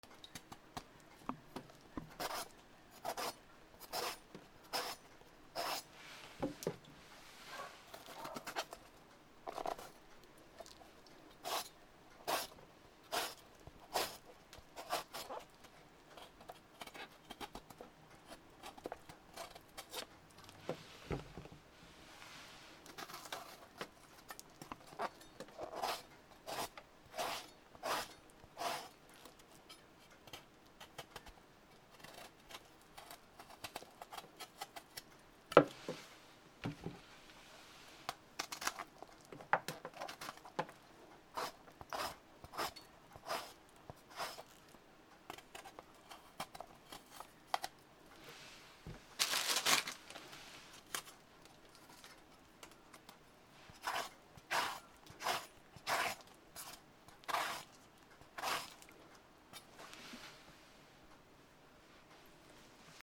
なすびを切る 皮に切込みを入れる